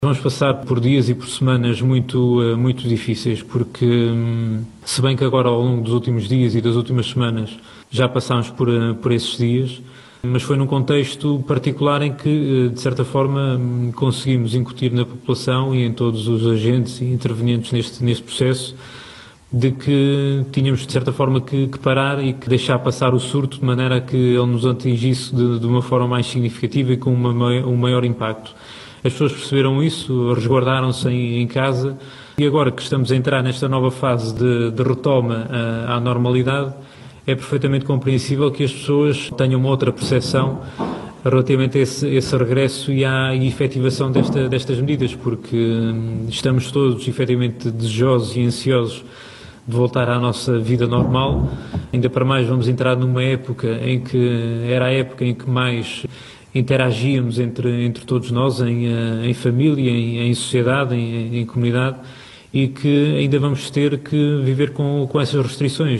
Tiago Lopes, que falava, no habitual briefing diário sobre a pandemia de Covid-19 na Região, adiantou mesmo que os próximos dias e semanas serão “muito difíceis”.